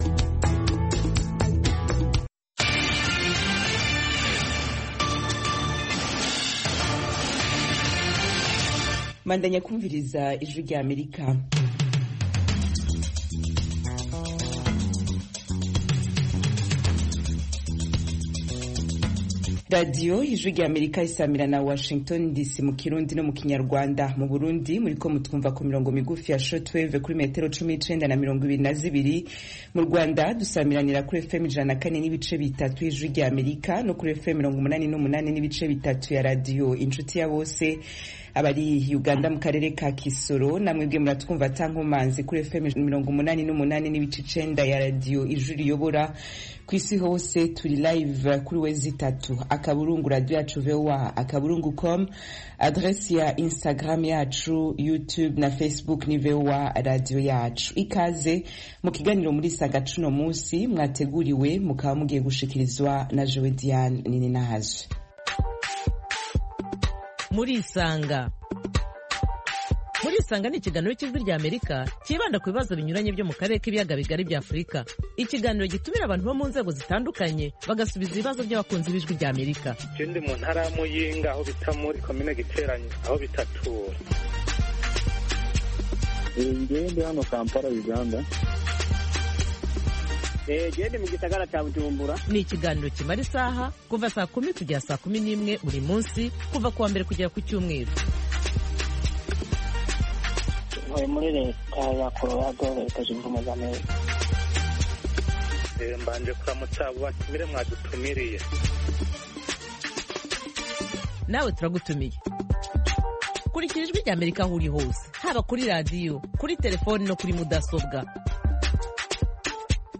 Mu kiganiro